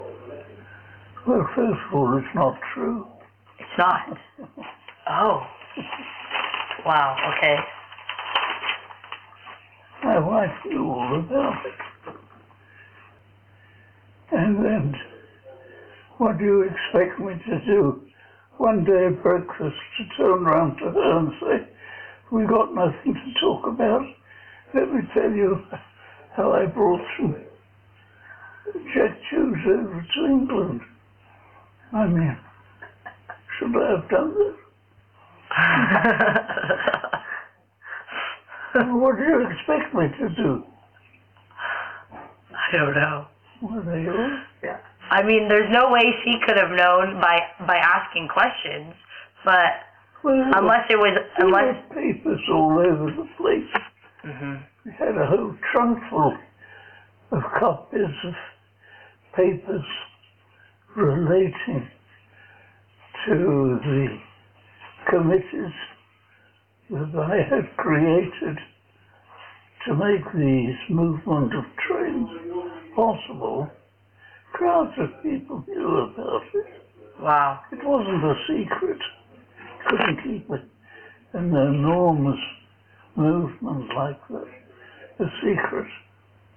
mluvil pomalu a některé otázky si musel nechat opakovat